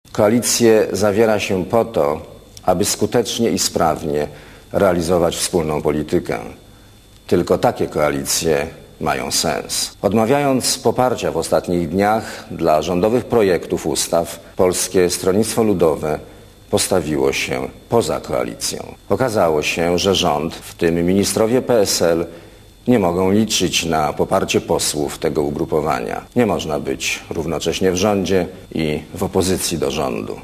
Koalicje zawiera się po to, aby skutecznie i sprawnie realizować wspólną politykę. Tylko takie koalicje mają sens - powiedział premier Leszek Miller w sobotnim wystąpieniu telewizyjnym po rozpadzie...